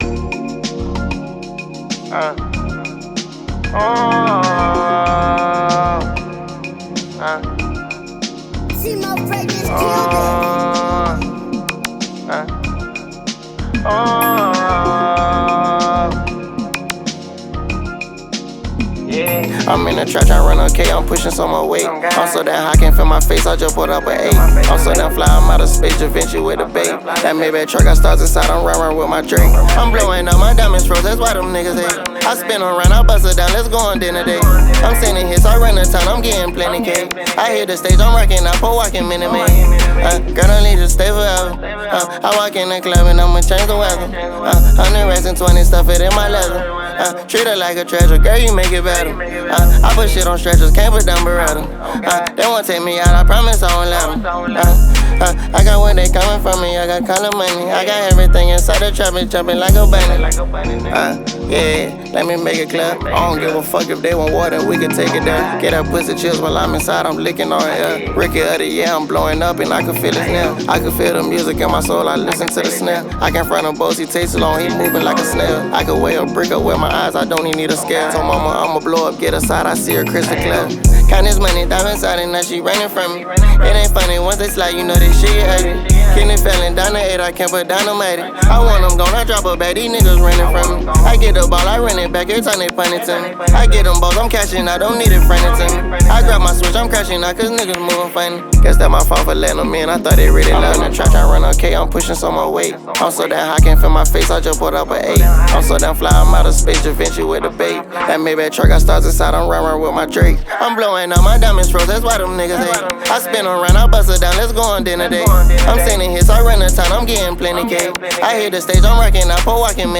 Prominent American rapper and performer